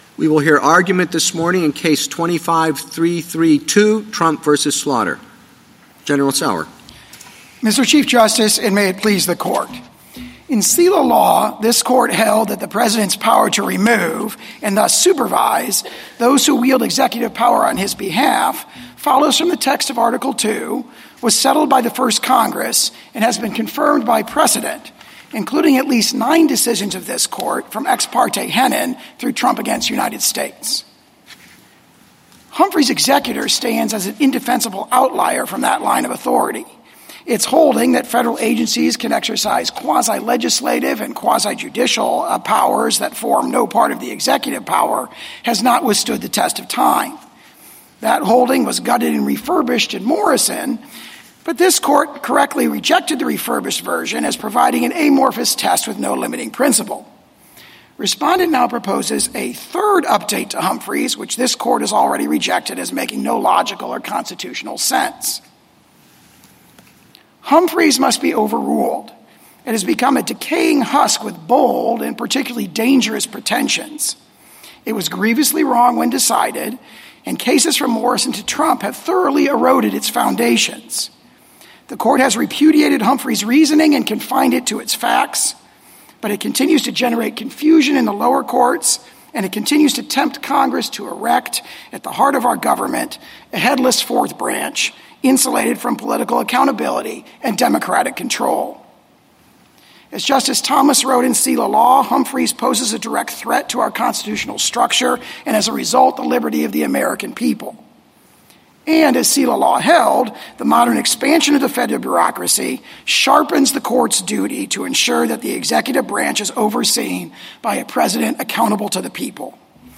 Oral Argument - Audio